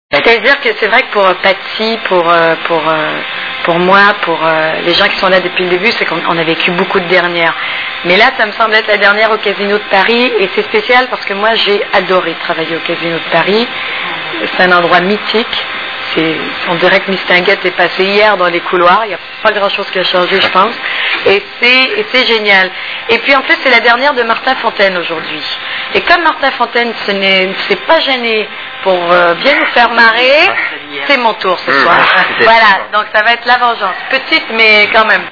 STARMANIA...Interview de Jasmine Roy !!!
( Casino de Paris, Hall d’entrée, 06/02/2000 )